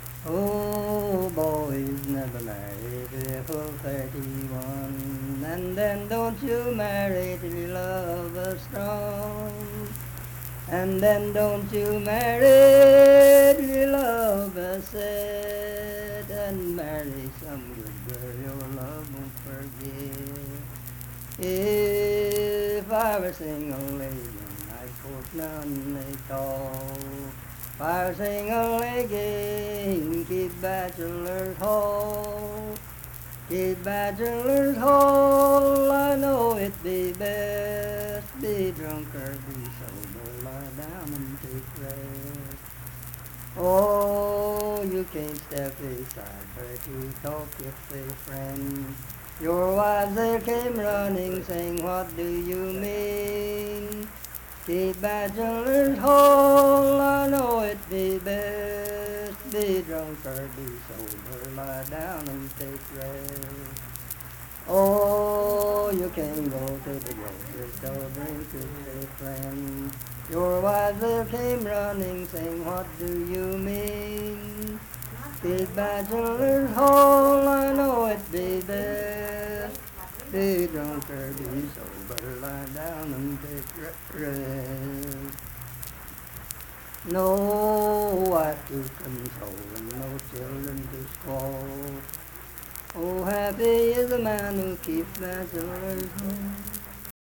Unaccompanied vocal music
Voice (sung)
Harts (W. Va.), Lincoln County (W. Va.)